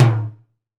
LTIMBALE L1R.wav